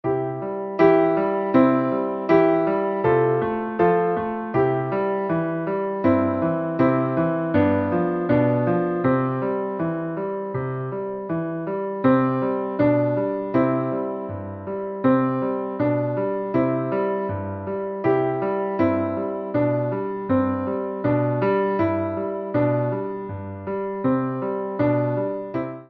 八分音符で動きのある伴奏になっています。